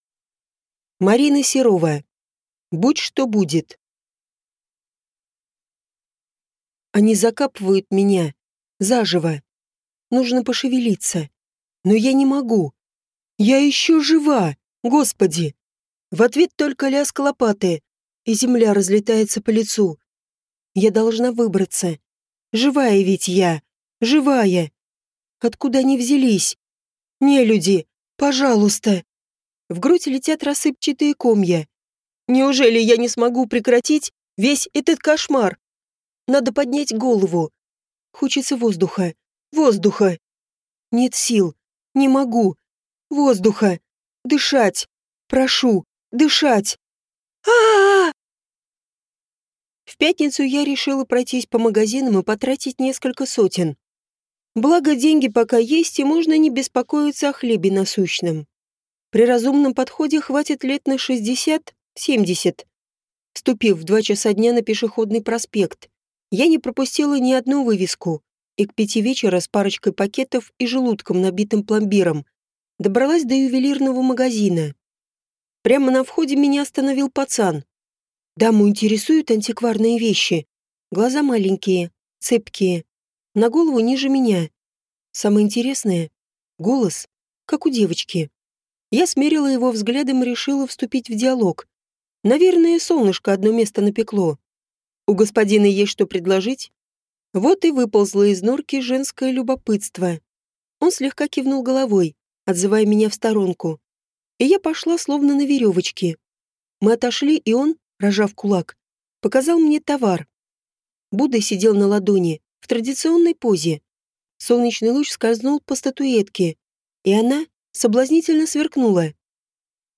Аудиокнига Будь что будет | Библиотека аудиокниг
Прослушать и бесплатно скачать фрагмент аудиокниги